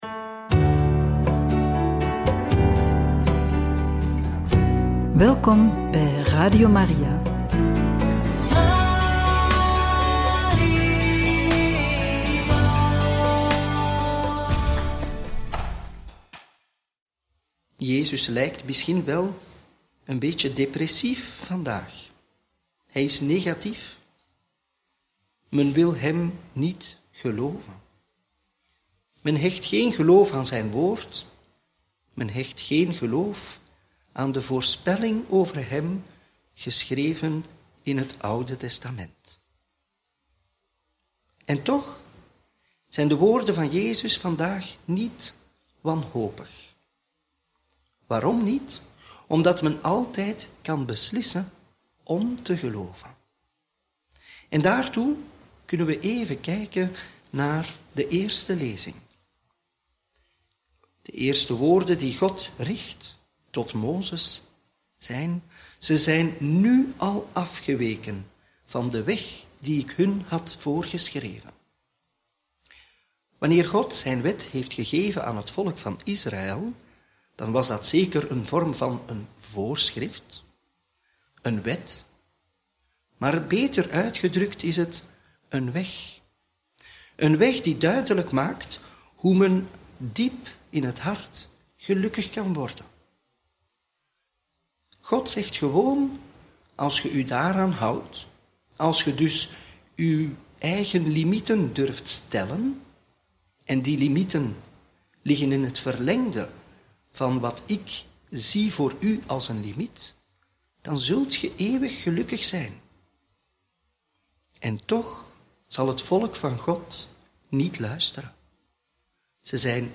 Homilie bij het Evangelie van donderdag 3 april 2025 – Joh. 5, 31-47